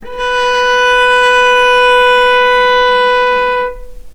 vc-B4-mf.AIF